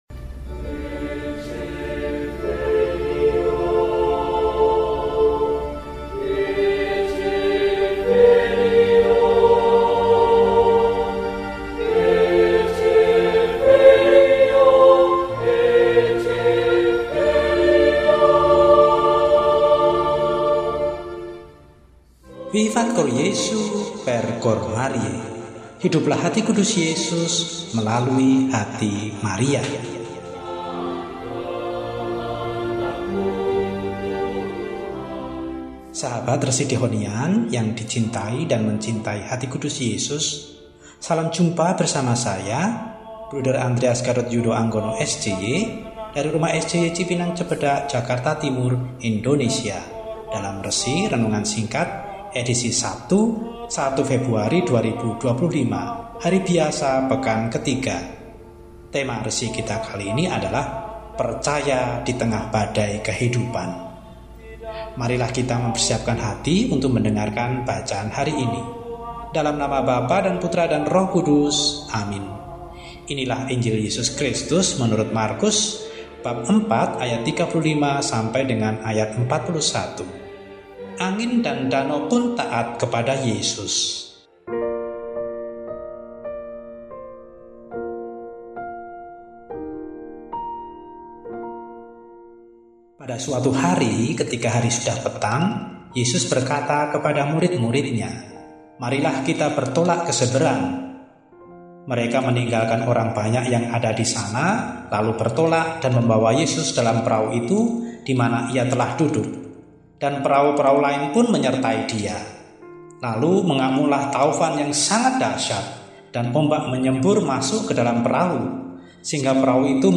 RESI DIBAWAKAN OLEH